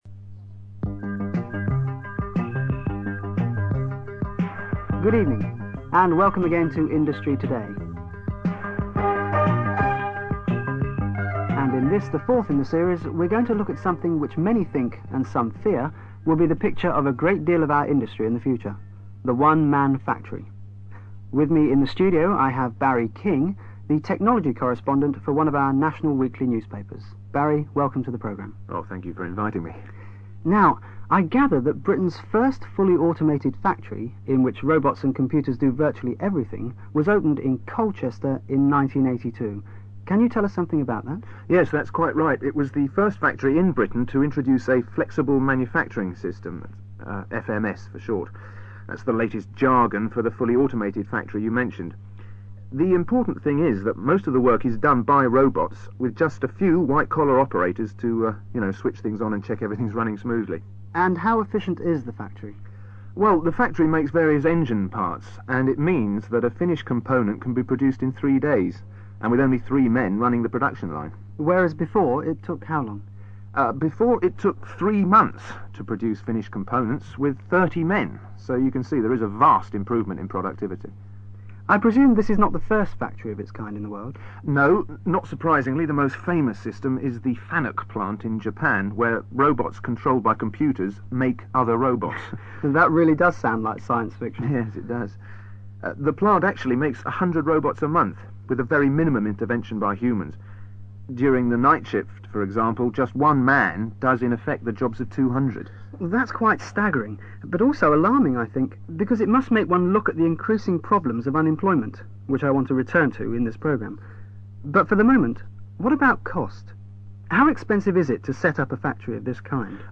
Comprehension
ACTIVITY 167: You are going to listen to a technology correspondent being interviewed on a radio features programme.